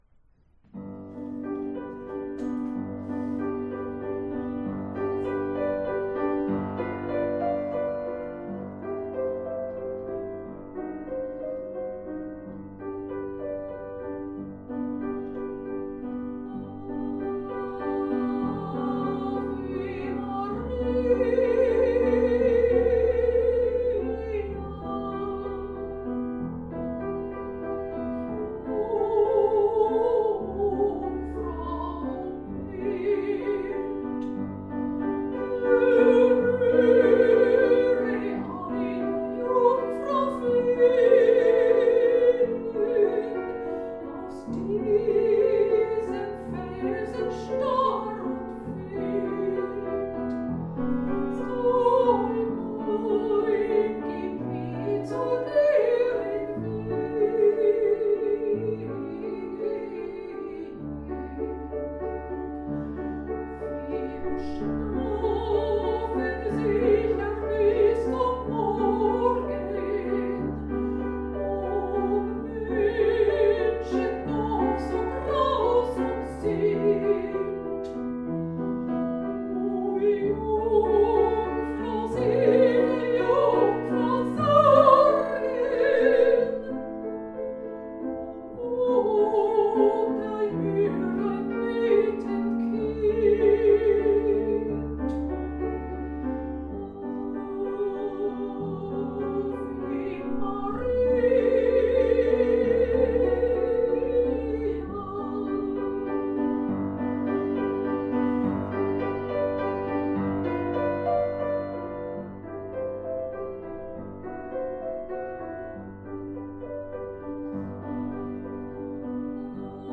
Liederabend im Studio für Gesang Berlin.
am Klavier